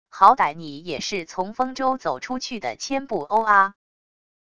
好歹你也是从丰州走出去的千部o阿wav音频生成系统WAV Audio Player